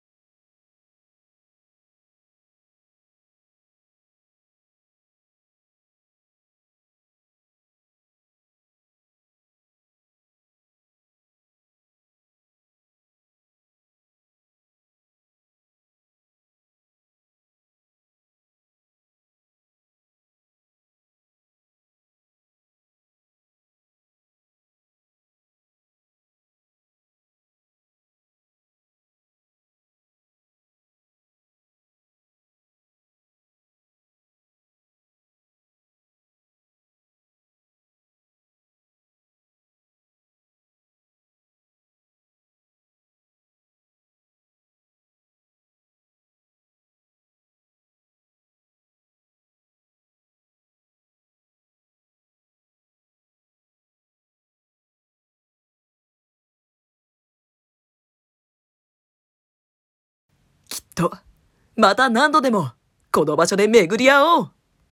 【歓声付き】